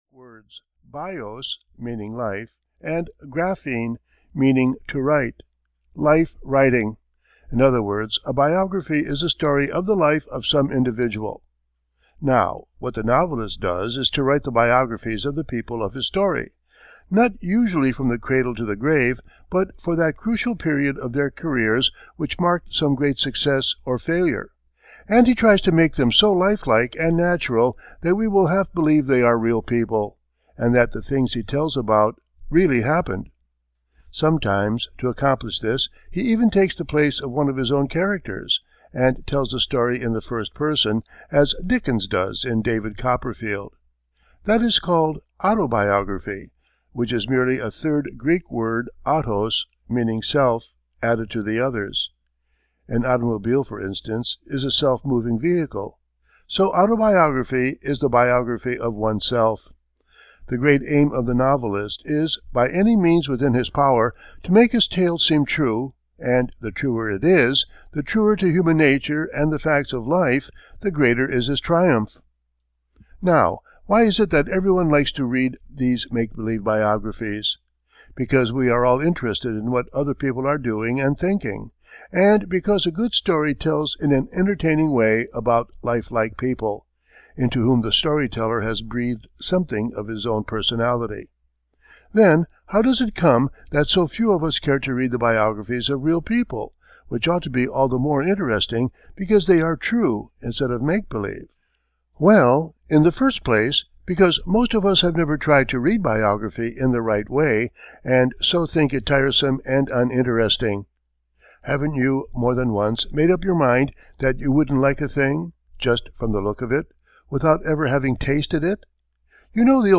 American Men of Action (EN) audiokniha
Ukázka z knihy